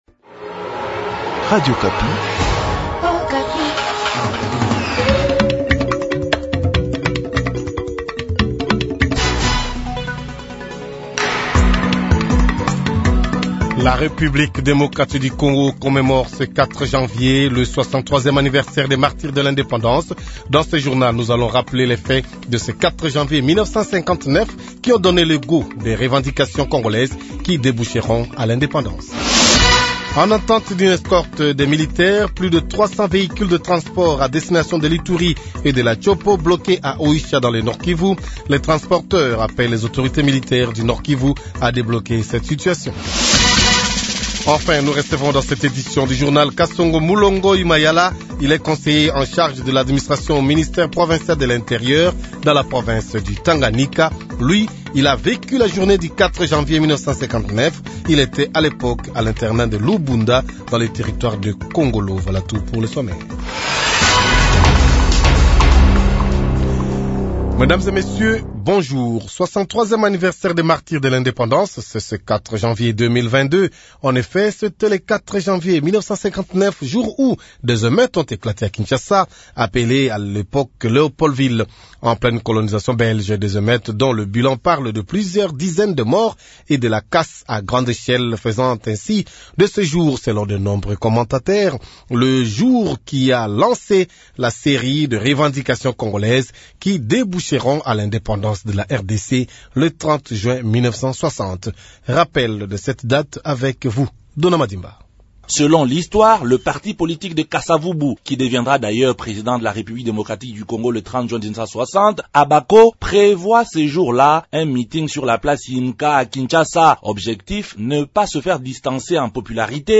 Kinshasa : Celebration de la Journée des martyrs, reportage à la place YMCA